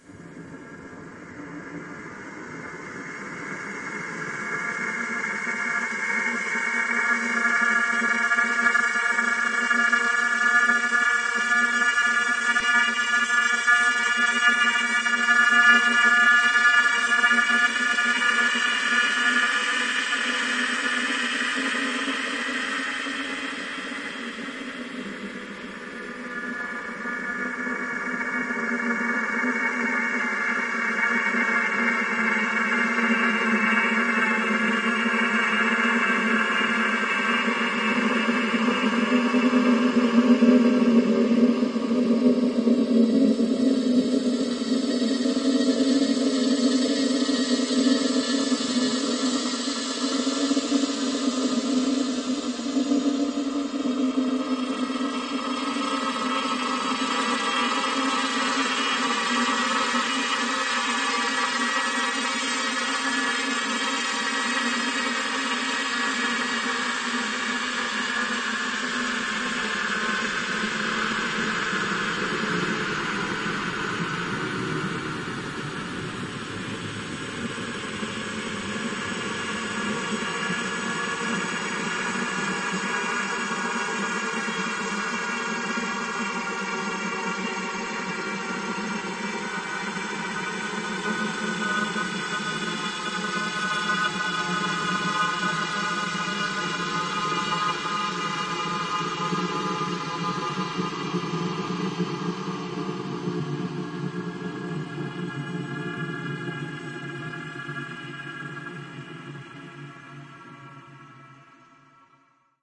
描述：通过重叠不同的音高变换无人机创建的无人机
Tag: 雄蜂预兆 可怕